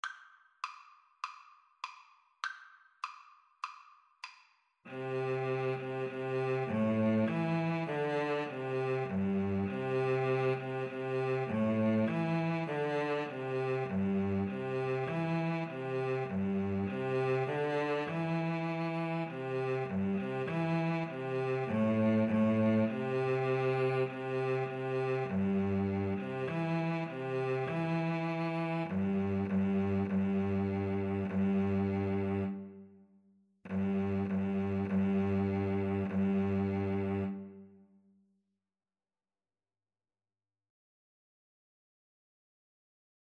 2/2 (View more 2/2 Music)